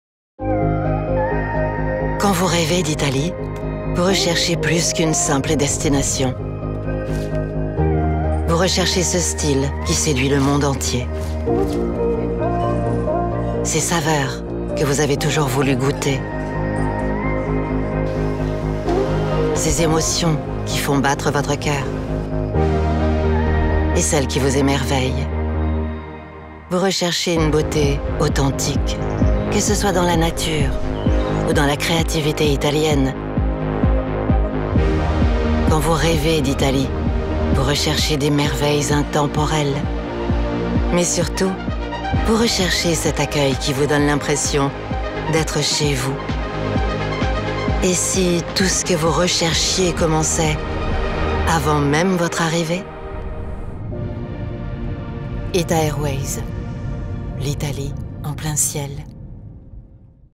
Female
Approachable, Confident, Corporate, Friendly, Reassuring, Versatile, Warm
E-Learning-E-Bay-Approachable.mp3
Microphone: Neumann TLM 103
Audio equipment: RME Fireface UC, separate Soundproof whisper room